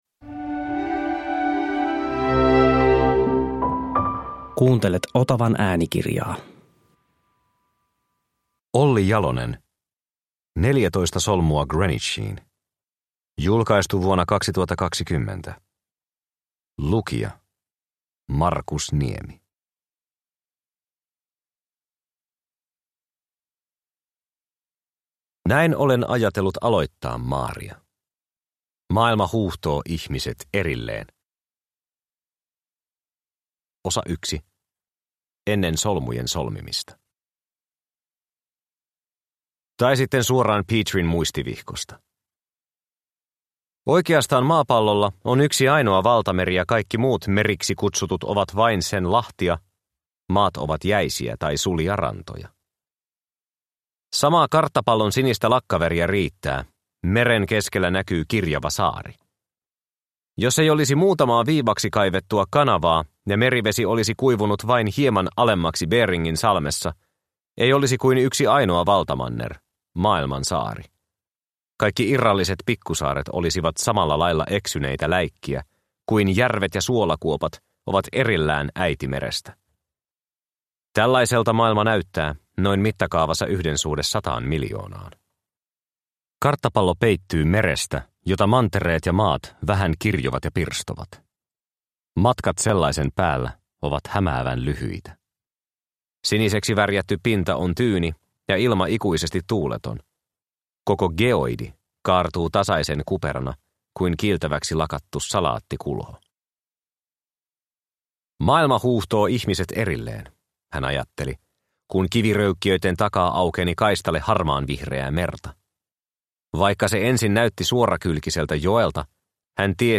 14 solmua Greenwichiin – Ljudbok – Laddas ner